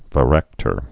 (və-răktər, vă-)